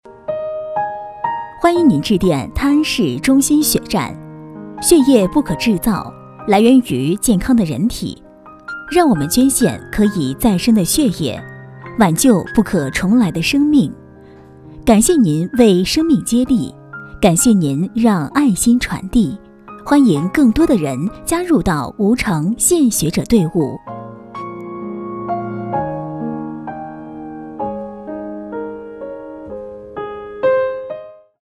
定制彩铃-女3-血站彩铃.mp3